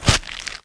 STEPS Soft Plastic, Walk 04.wav